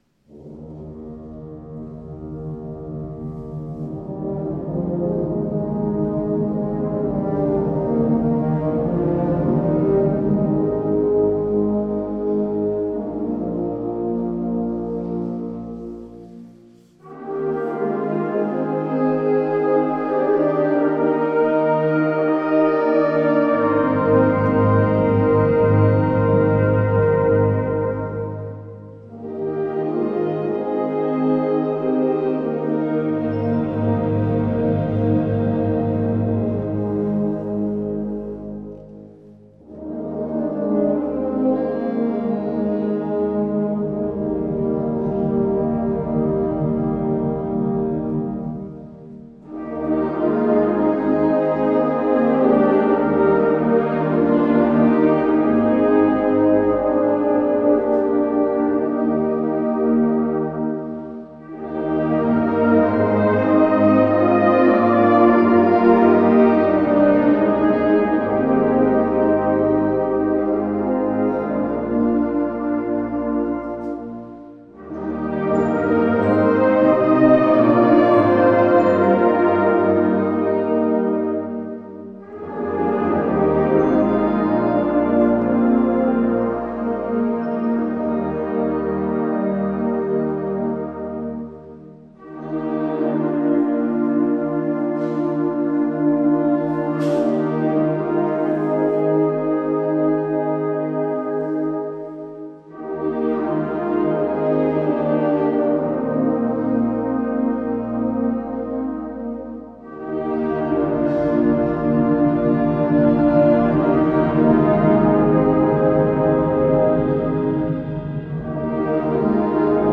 Kirchenkonzert 2024
Unter dem Motto "Musik zur Ehre Gottes" durften wir in der Pfarrkirche Untermieming ein Konzert darbieten.